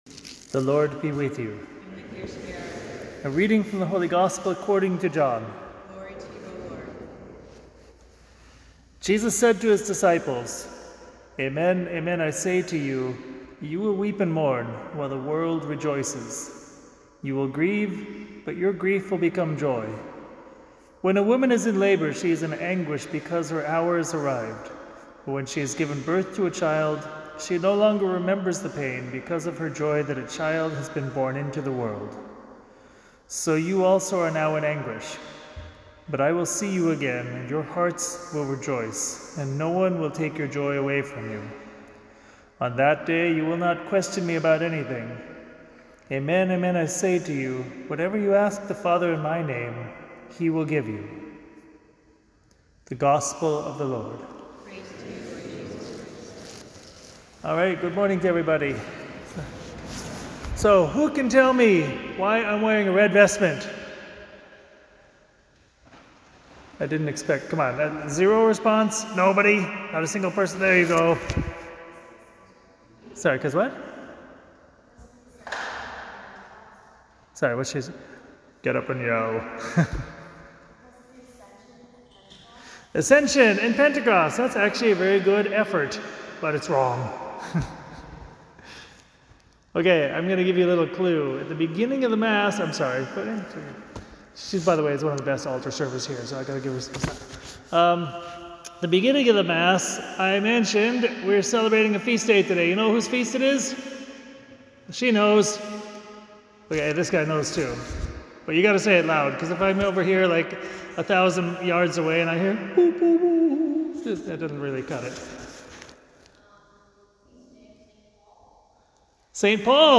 St. Damien - Skin in the Game - RC NY Tri-State